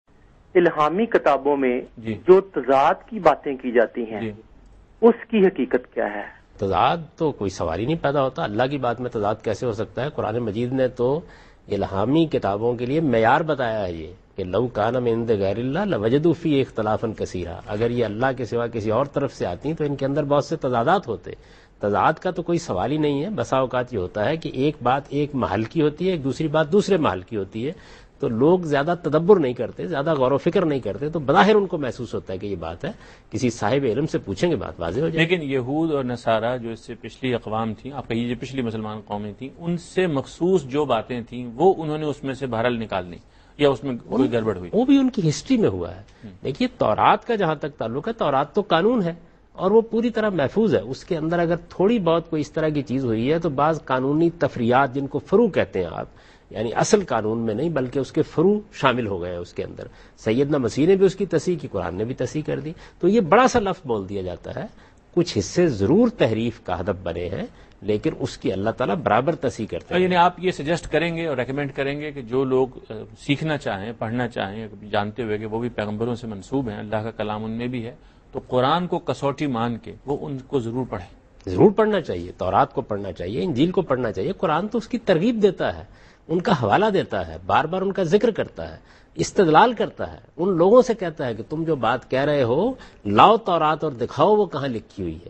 Javed Ahmad Ghamidi answers a question about "Contradictions among Divine Books" in program Deen o Daanish on Dunya News.
جاوید احمد غامدی دنیا نیوز کے پروگرام دین و دانش میں الہامی کتابوں میں تضادات سے متعلق ایک سوال کا جواب دے رہے ہیں